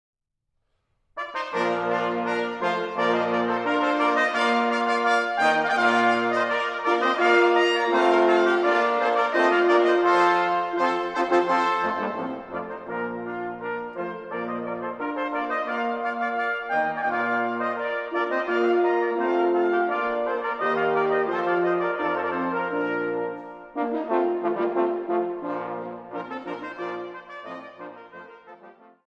To test the concept, I'm trying a couple of different formats - electronic recordings of music, snippets of sheet music of well-know compositions, and, of course, pictures of cars.